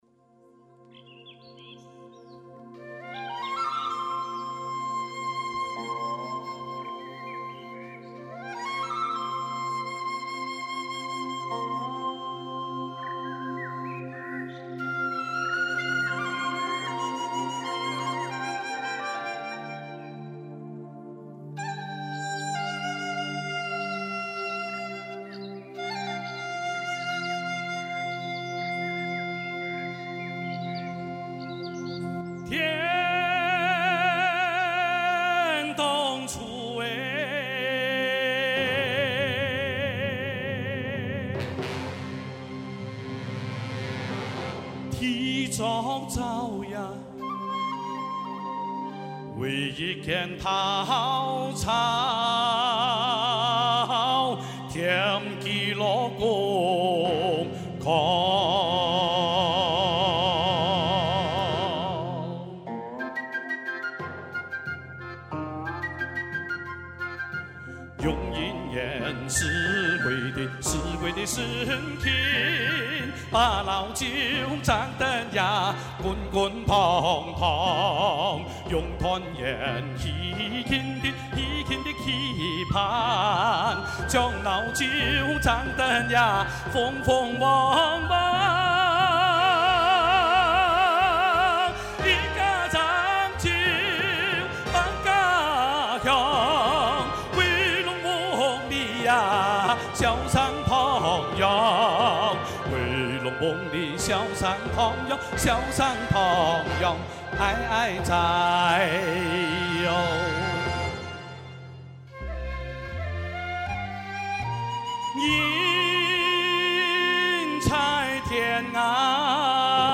《客家老酒》 - 客家传统歌曲